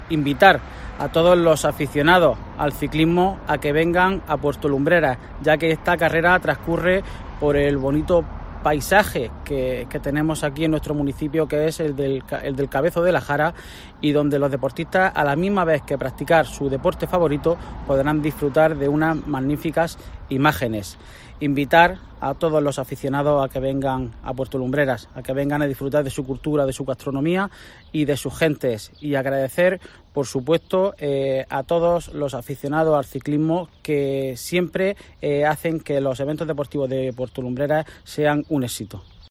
Juan Rubén Burrueco, edil de deportes de Puerto Lumbreras